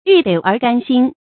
欲得而甘心 yù dé ér gān xīn 成语解释 想要弄到手才称心满意（多用于对人的报复或打击）。